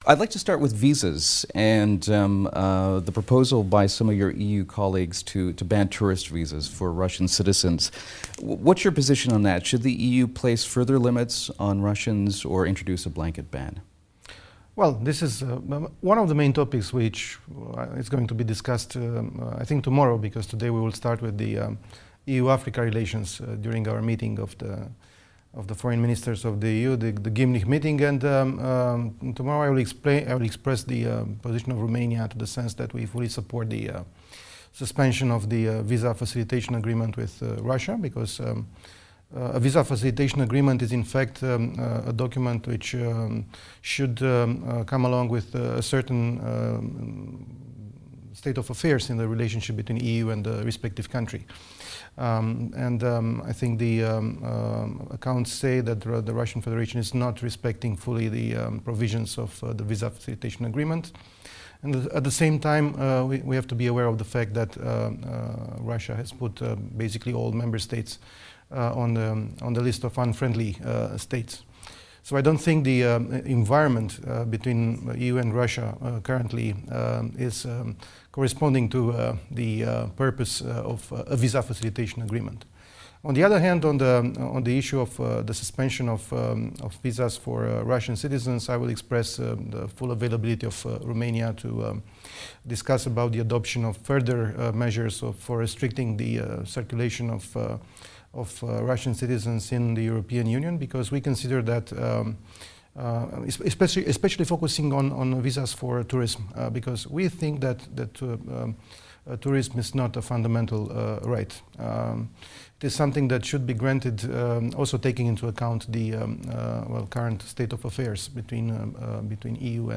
Întrebat care este poziția României în privința propunerii de suspendare a înțelegerii cu Moscova privind acordarea de vize rușilor în regim preferențial, șeful diplomației române Bogdan Aurescu a declarat într-un interviu pentru Europa Libere că Bucureștiul se pronunță în favoarea suspendării...